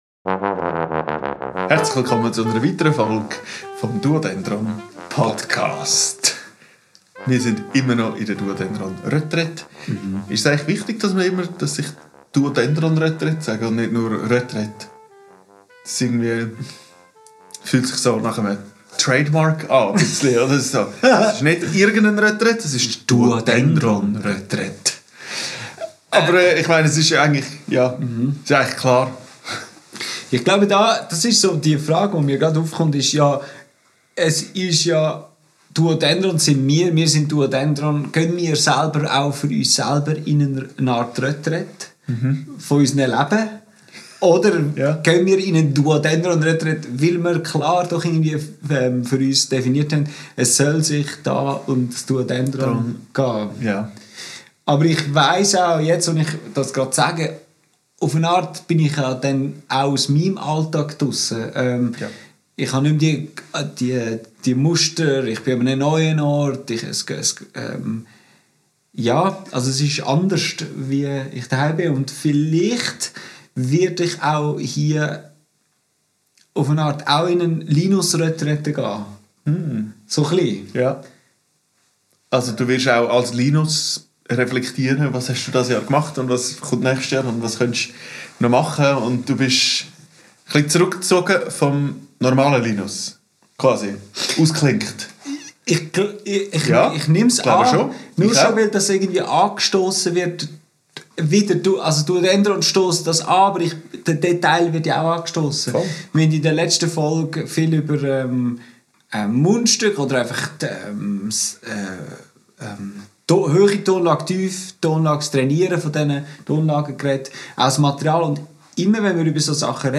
Aufgenommen am 03.12.2025 in Rovio.